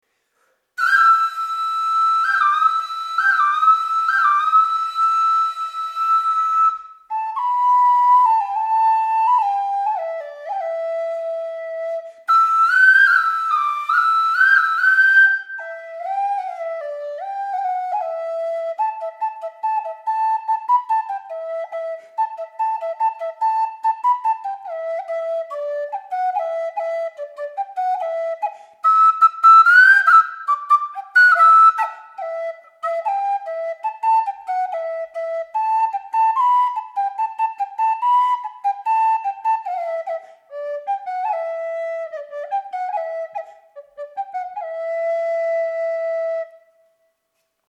Сопель
Сопель Тональность: D
Сопель — русская продольная свистковая флейта, изготовленная из дерева.
По сравнению с сопелью из сосны, сопель из ясеня имеет более четкий звук, с меньшим количеством “шипения” и “воздуха”.